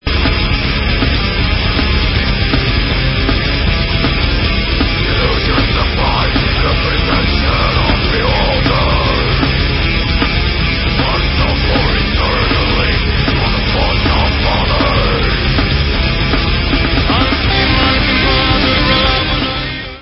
sledovat novinky v kategorii Rock
sledovat novinky v oddělení Heavy Metal